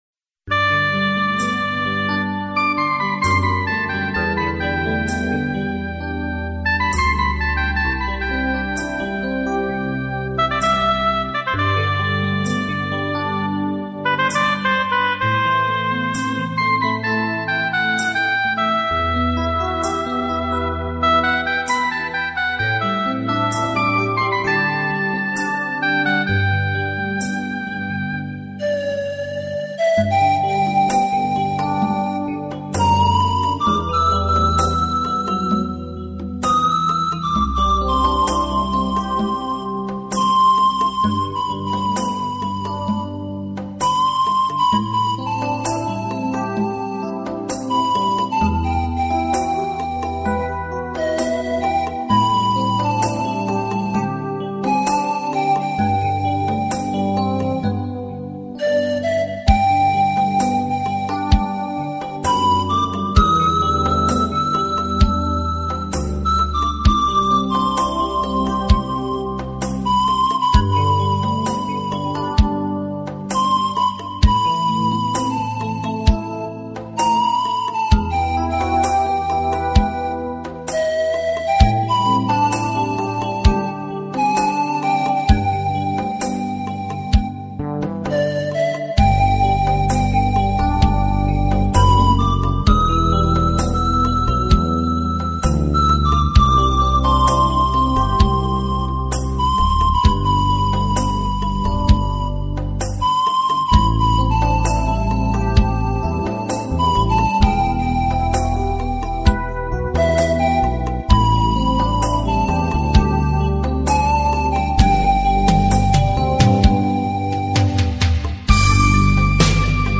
排笛的声音略带瘖哑，有着一种苍凉的独特风味，十分迷人，因此特别